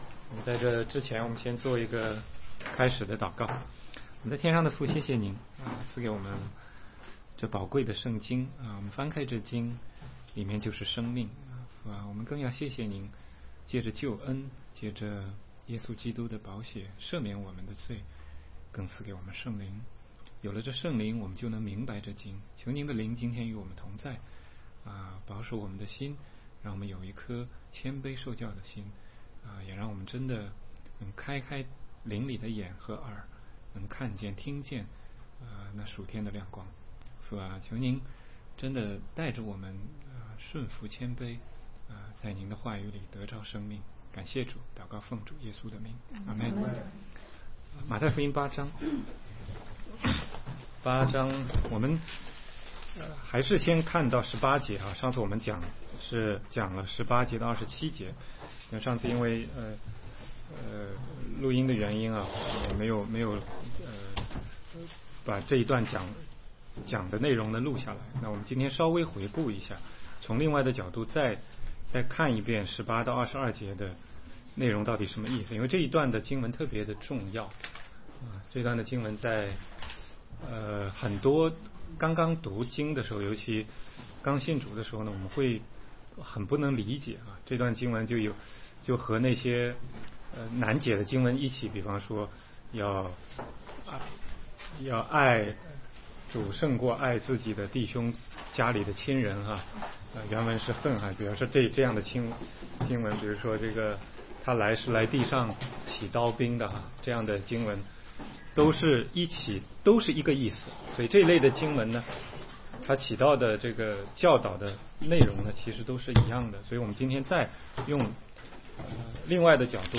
16街讲道录音 - 马太福音8章28-34节